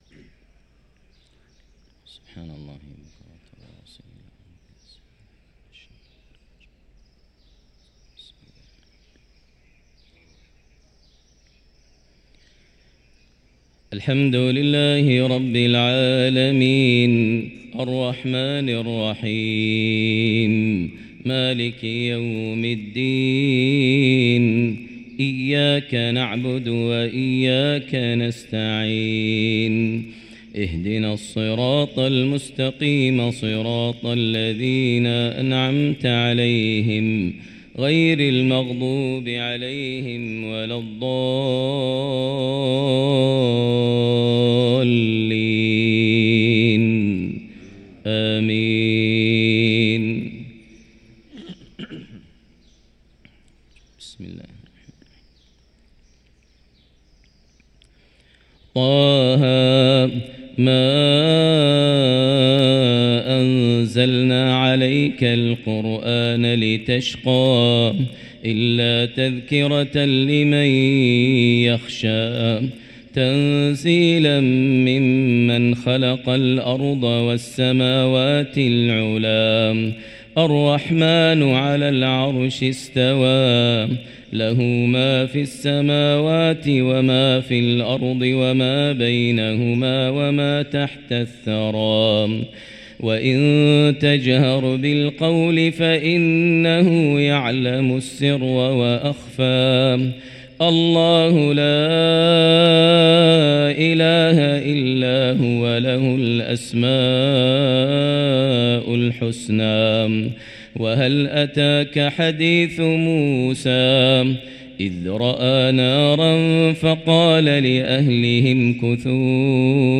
صلاة الفجر للقارئ ماهر المعيقلي 6 رجب 1445 هـ
تِلَاوَات الْحَرَمَيْن .